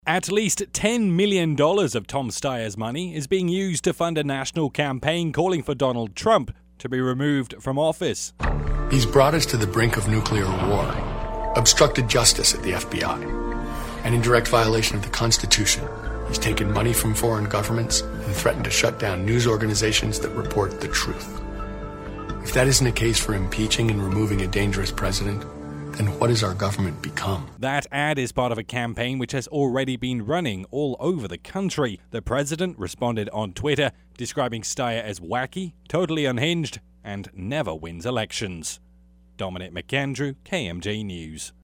report
as it aired